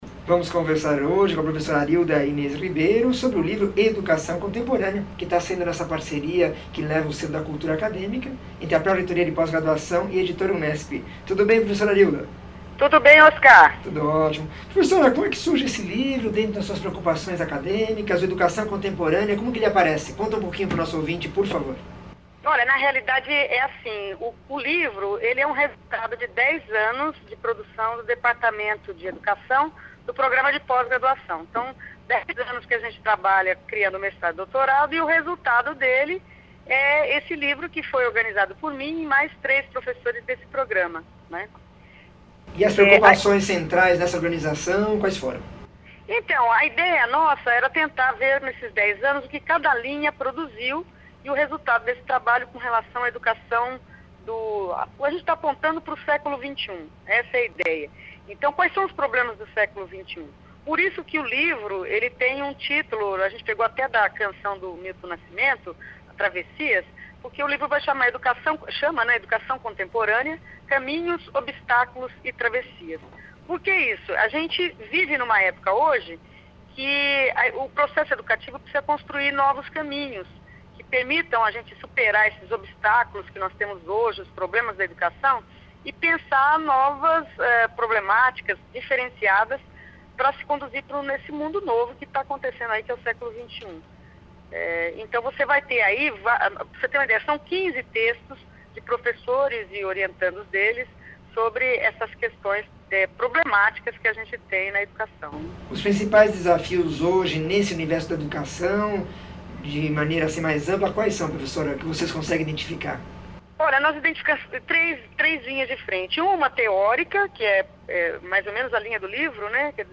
entrevista 1413